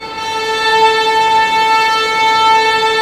Index of /90_sSampleCDs/Roland LCDP13 String Sections/STR_Violins FX/STR_Vls Sul Pont